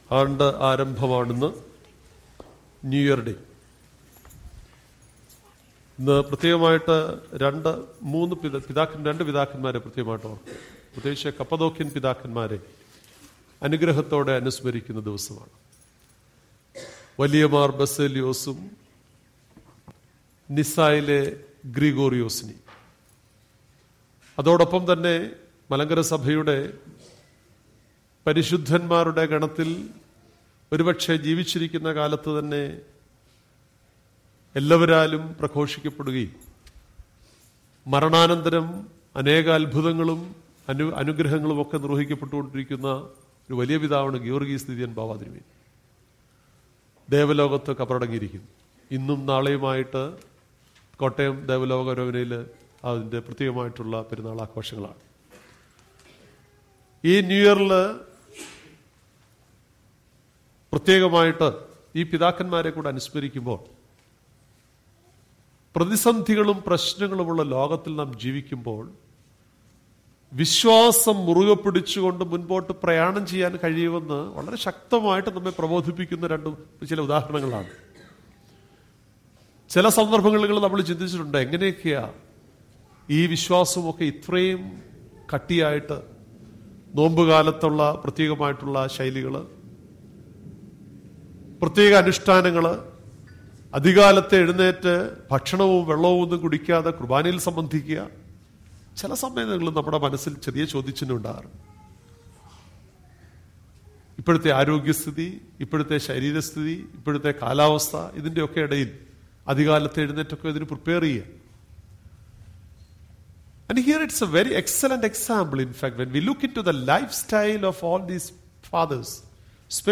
Denaha(Epiphany) Day Sermon